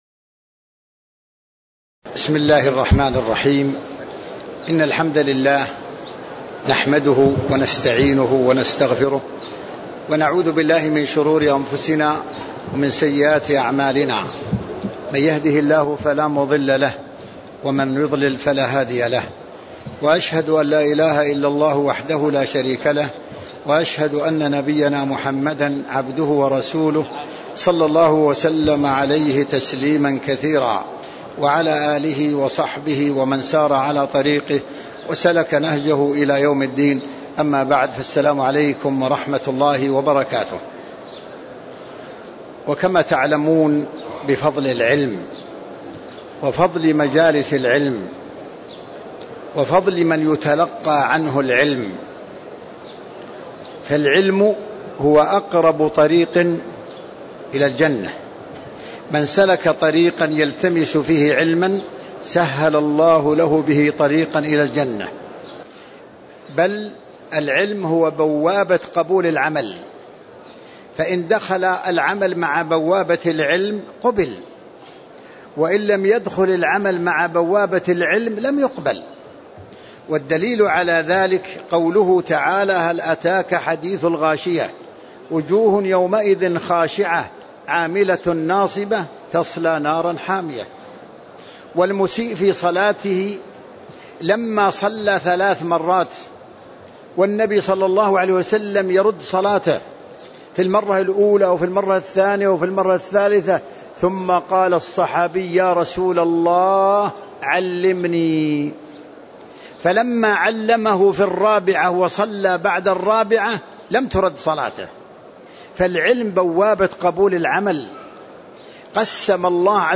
تاريخ النشر ١٤ ذو القعدة ١٤٣٦ هـ المكان: المسجد النبوي الشيخ